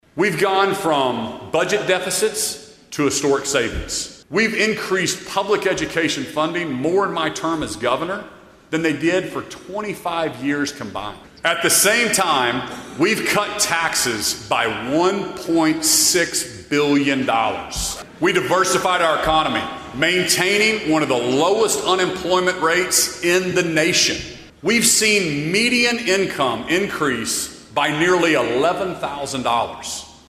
Stitt Delivers Final State Address
On Monday afternoon, Oklahoma Governor Kevin Stitt gave his final state of the state address.